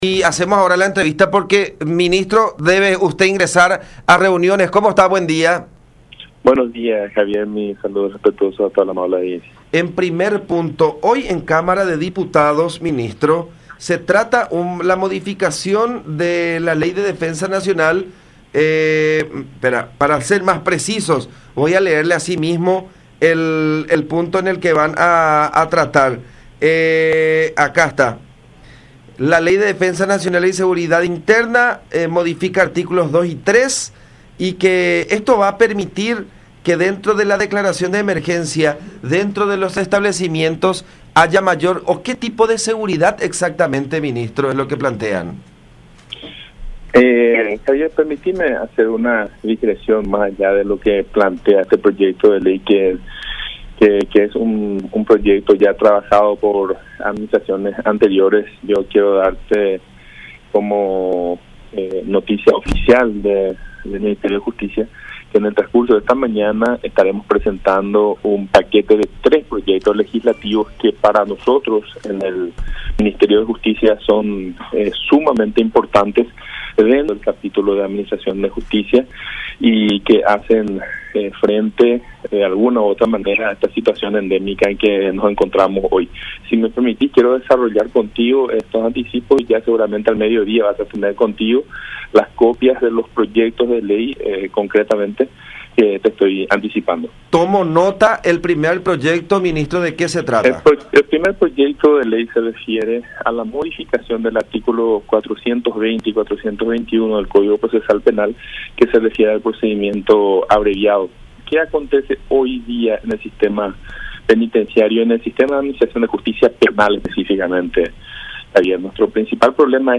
Para reducir el hacinamiento de presos sin condena, que se produce por la sobresaturación de causas penales y la imposibilidad de desarrollar trámites más ágiles para definir procesos de menor complejidad”, explicó el secretario de Estado en comunicación con La Unión.
09-Eber-Ovelar-Ministro-de-Justicia.mp3